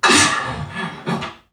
NPC_Creatures_Vocalisations_Robothead [22].wav